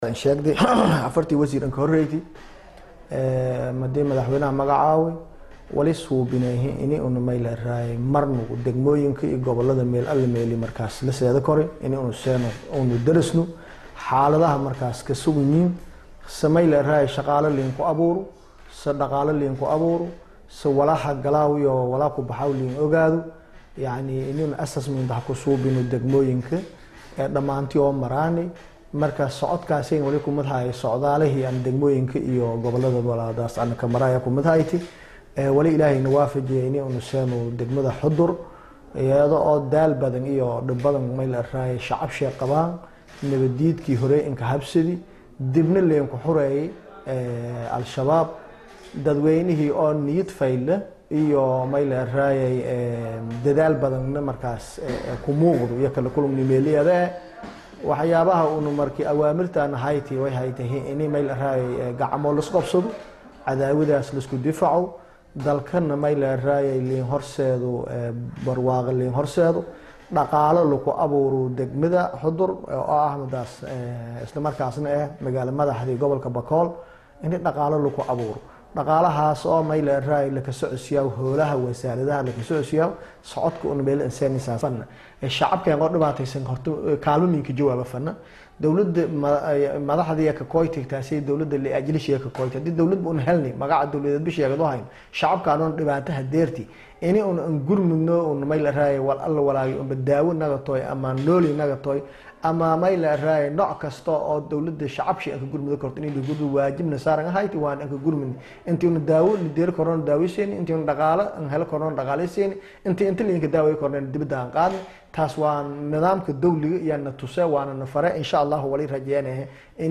Wasiirka Oo Wareysi Siinayey TV-ga Jubbaland ayaa sheegey inay ku guuleysteen dhismaha Bank dhexe ee Magaalada Baydhabo isla markaana waxa gacan ku siisey Hay’ad SSF.